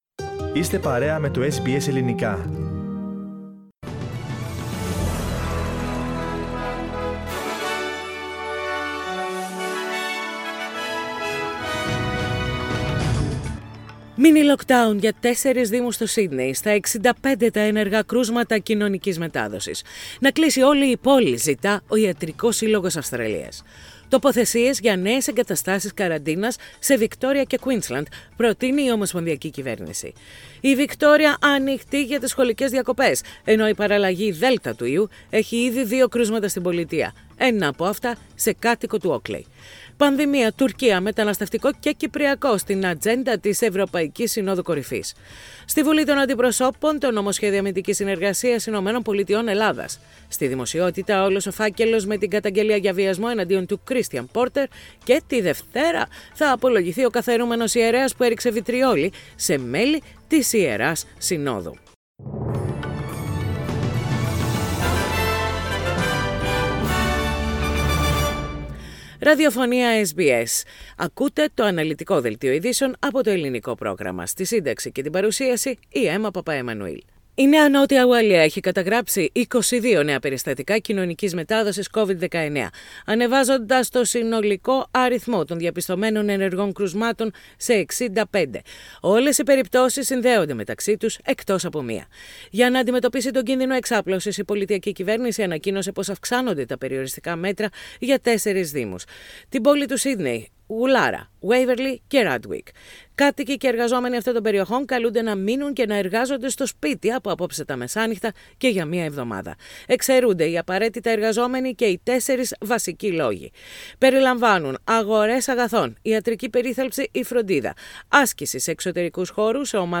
News in Greek - Friday 25.6.21